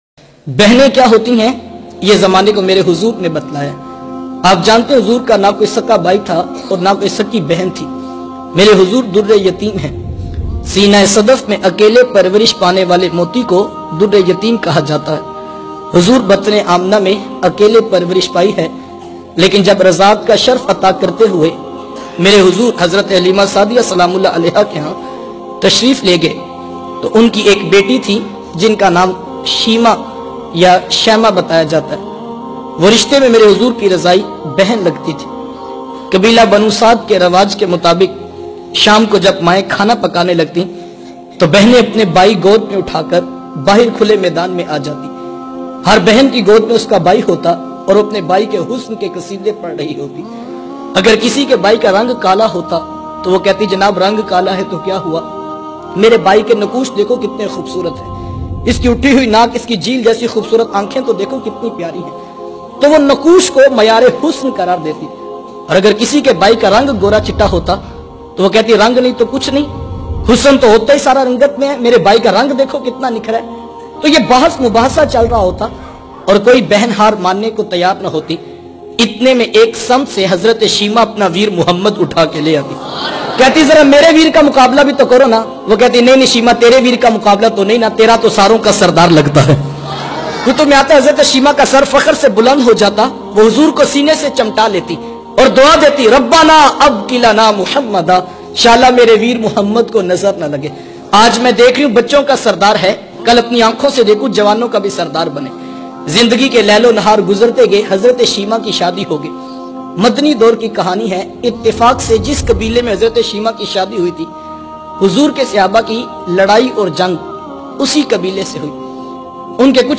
JAB BEHNE DARPE AATI HE TO KHALI NAHI LOTAYI JATi bayan